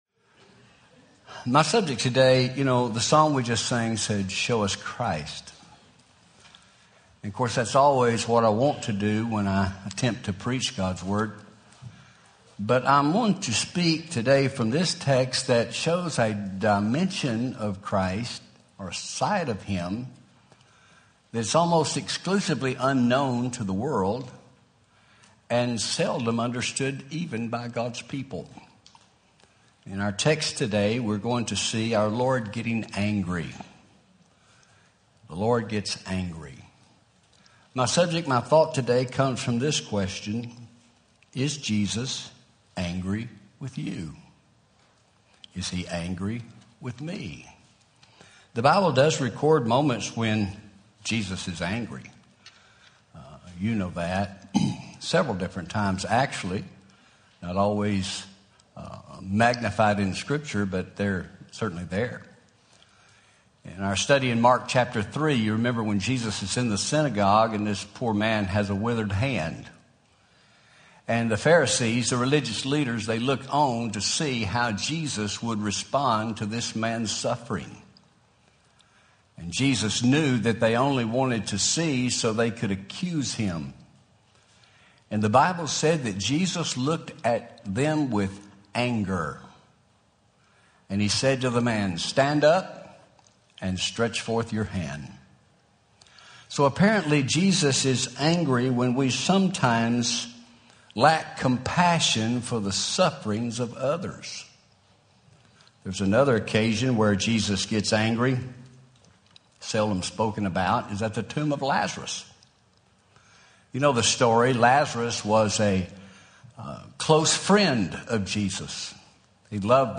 Home › Sermons › Is Jesus Angry With You